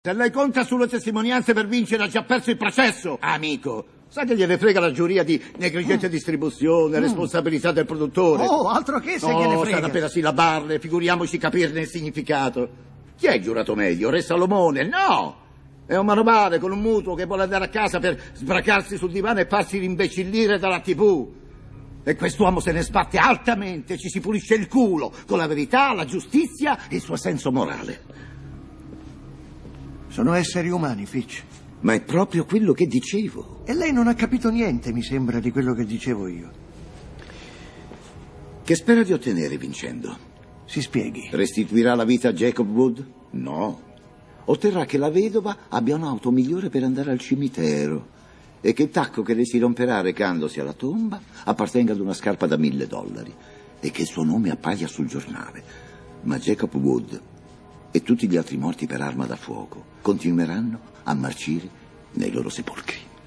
voce di Sergio Fiorentini nel film "La giuria", in cui doppia Gene Hackman.